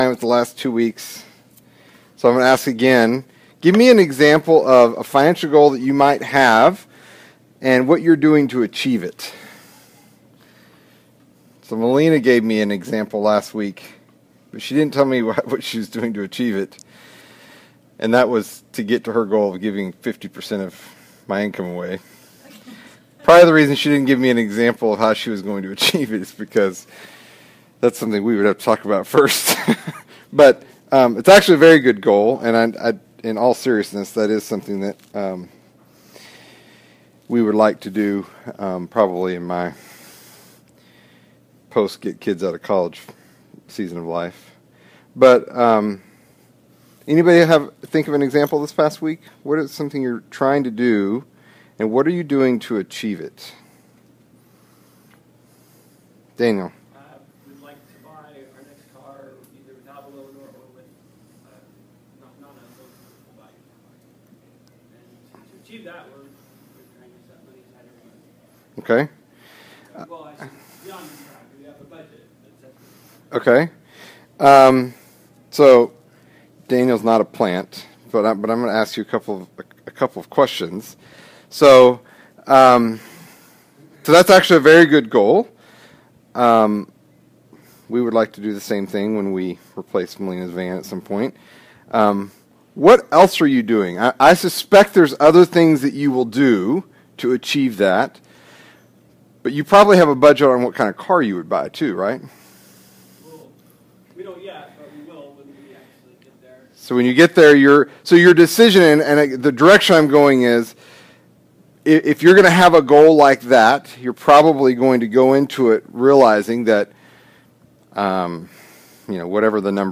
The second lesson on debt within our Sunday School series on Financial Stewardship.